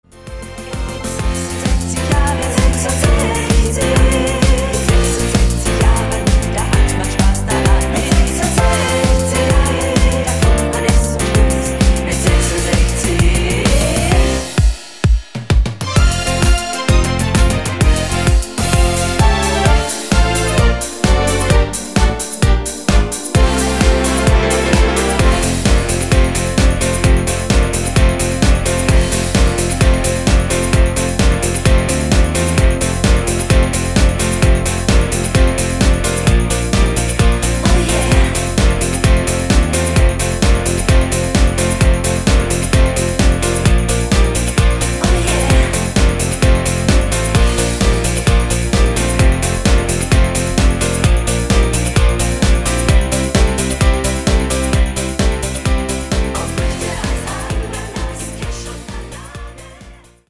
durchgängiger Rhythmus